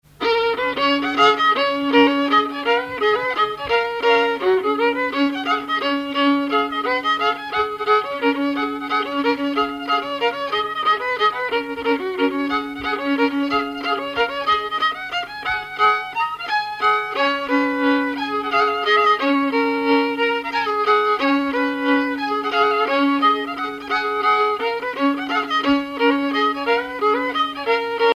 musique traditionnelle ; violoneux, violon,
danse : mazurka ; danse : java
Pièce musicale inédite